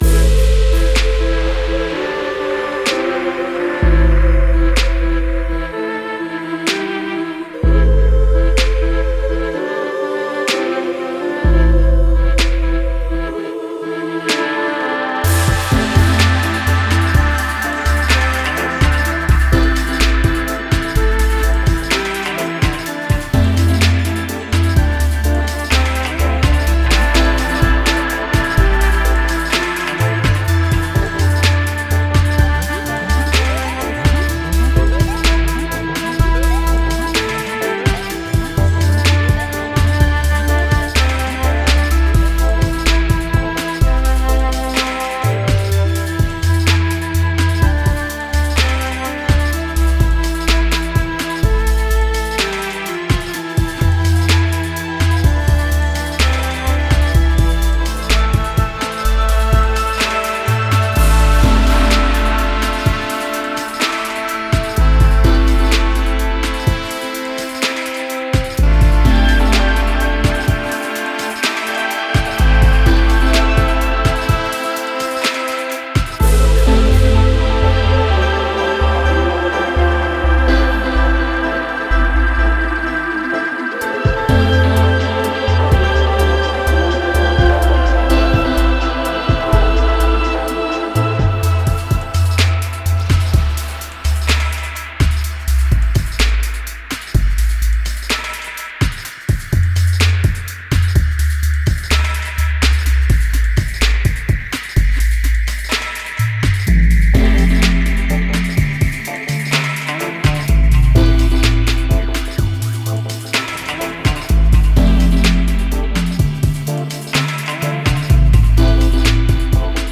Genre:Dub
このパックは、豊かな雰囲気とハーフステップのグルーヴを備え、革新的でダイナミックなトラックの基調を作り出します。
Half-Step Feel: 新たなアイデアを刺激する独自のリズムパターン。
Synth Brass & Arps: モダンなダブの雰囲気を演出する豊かで質感のあるレイヤー。
Choral Vocals & Skanks: 未来的なサウンドと融合したタイムレスな要素。
デモサウンドはコチラ↓